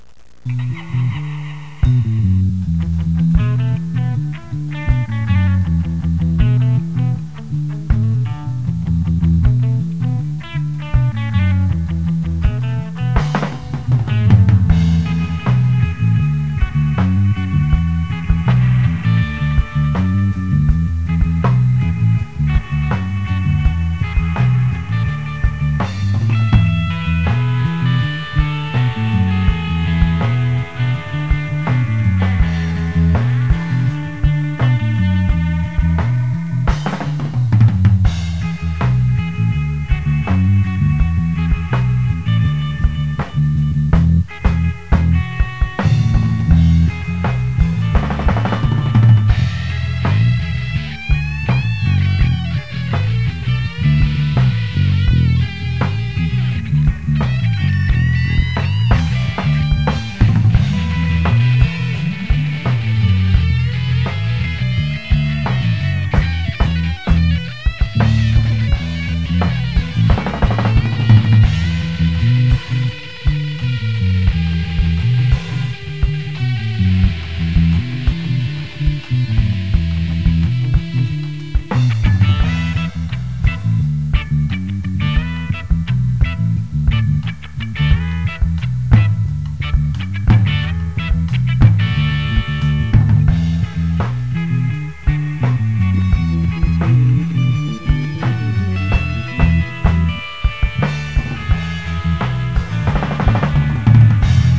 PUNKROCKREGGAERAPDUB